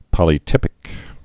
(pŏlē-tĭpĭk) also pol·y·typ·i·cal (-ĭ-kəl)